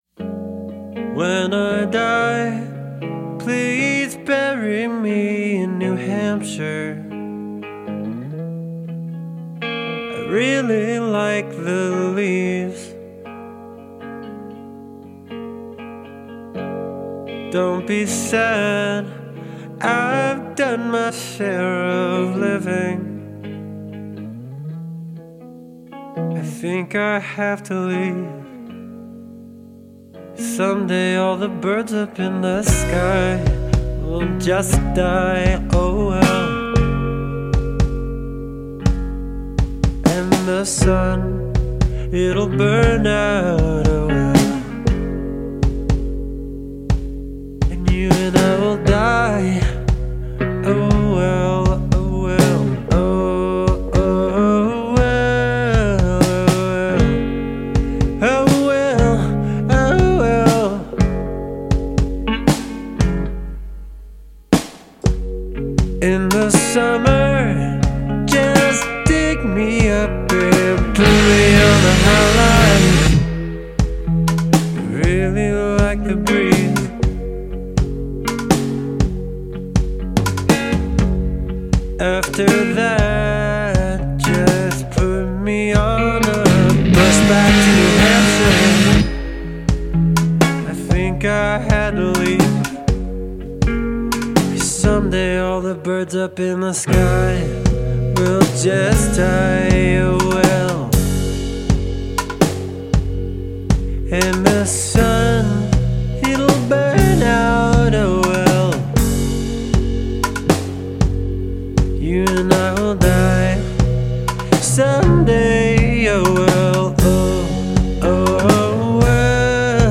ballad
voice and guitar
in a beautifully interwoven harmony